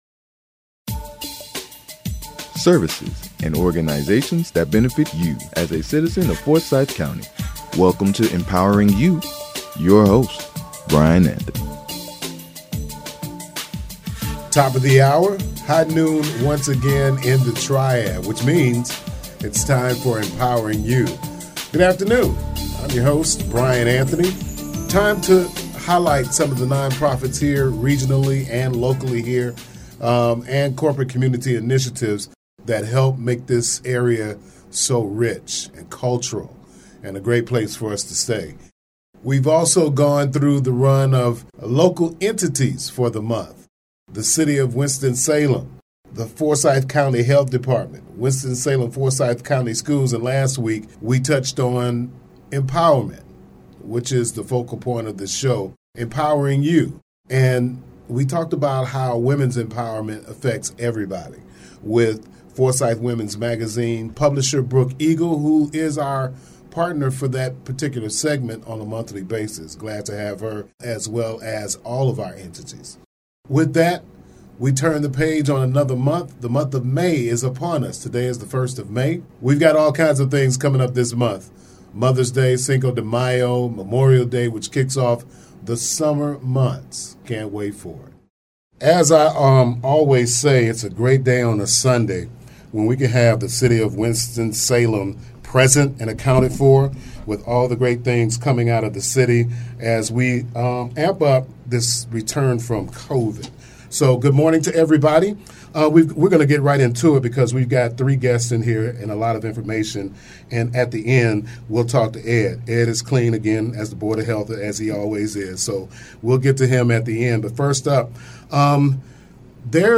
Empowering You is a 1-hour broadcast produced and recorded in the WSNC-FM Studios. The program is designed to highlight Non-Profit organizations, corporate community initiatives that are of benefit to the citizens of our community.